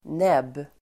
Uttal: [neb:]